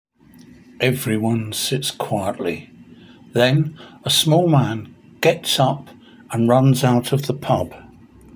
Each line is written in English and then in Spanish and has a recording of me reading it.